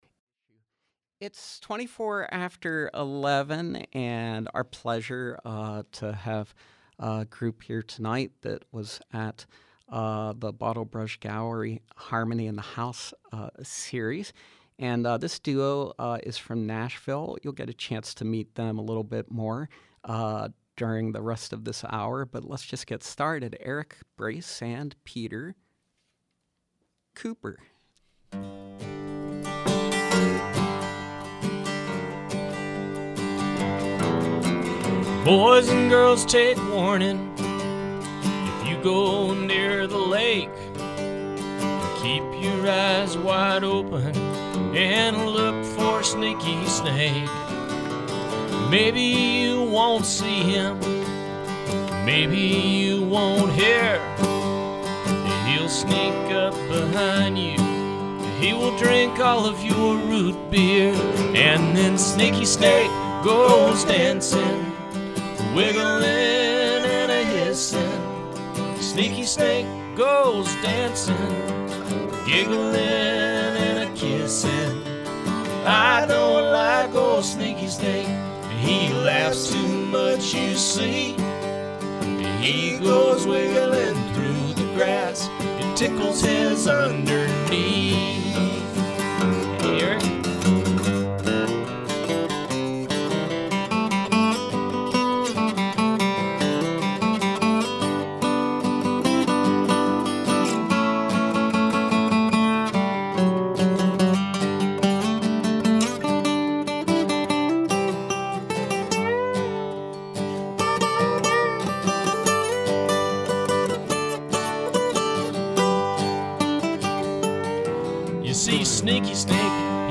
Live music with Nashville-based roots duo